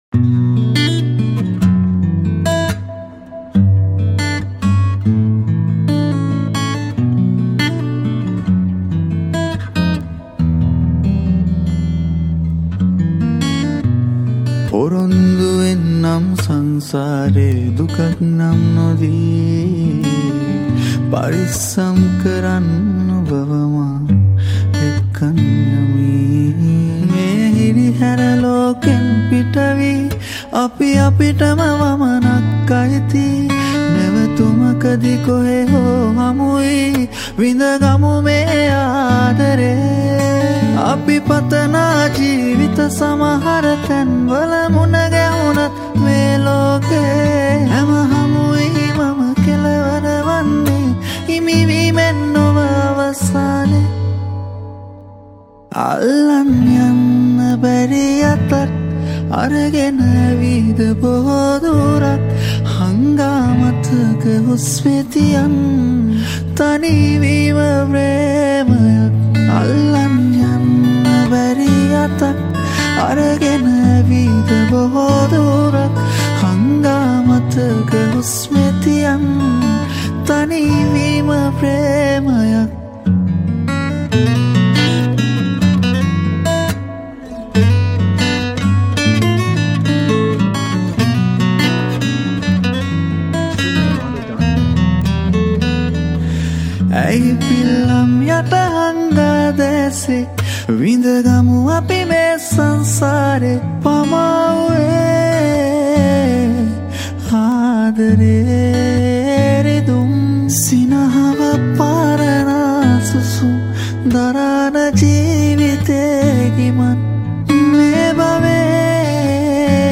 Acoustic Guitar